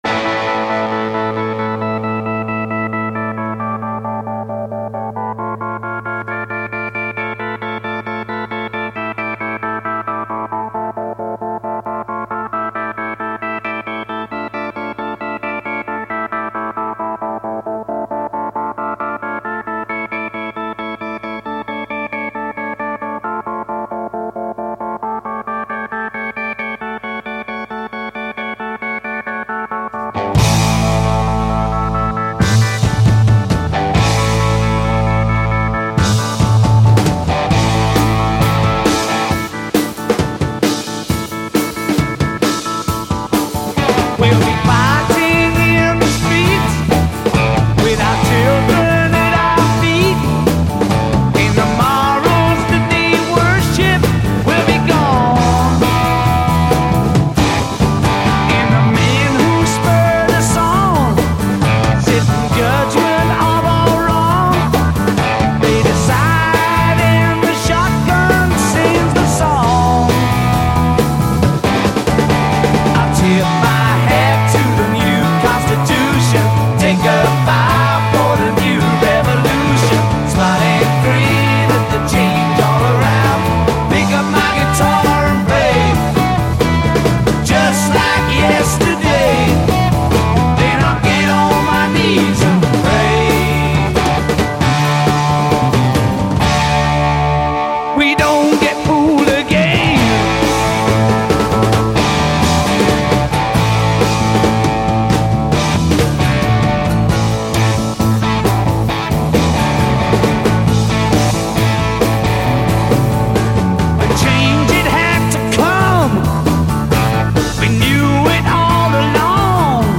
Die Musikauswahl kommt mal mehr, mal weniger weihnachtlich daher.